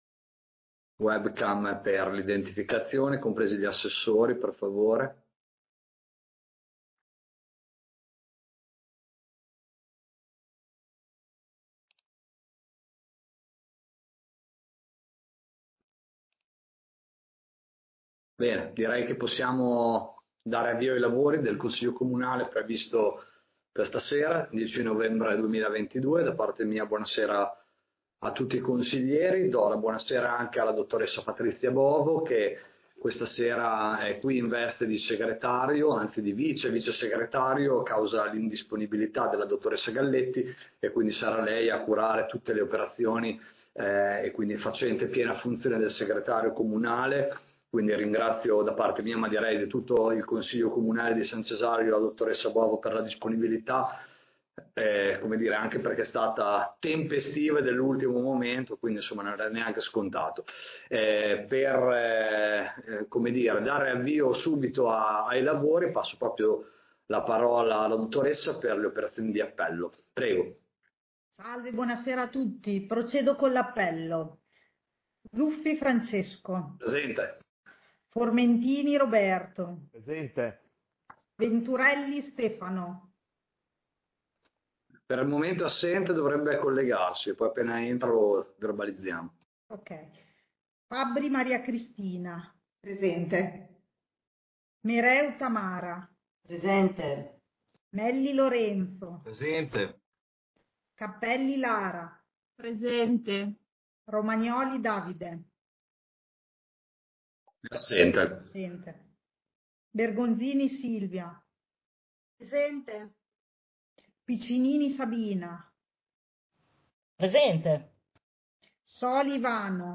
Consiglio Comunale del 10 novembre 2022